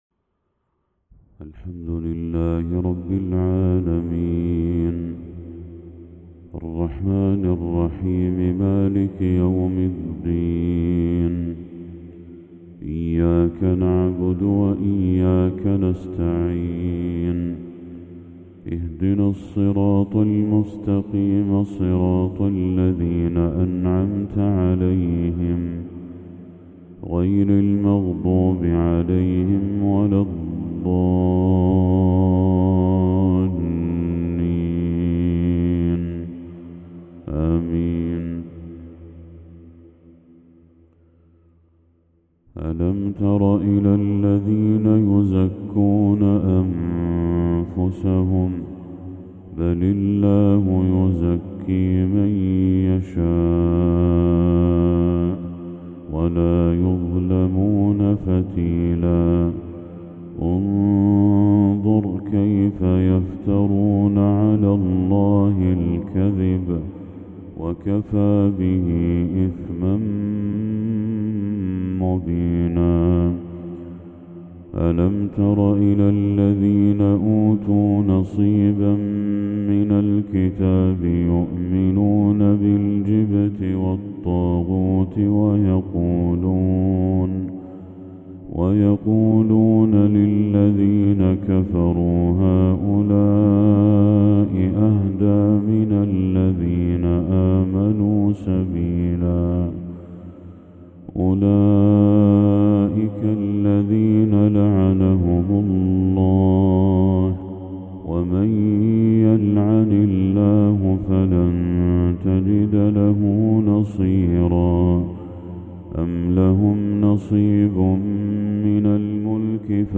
تلاوة شجية من سورة النساء للشيخ بدر التركي | فجر 4 محرم 1446هـ > 1446هـ > تلاوات الشيخ بدر التركي > المزيد - تلاوات الحرمين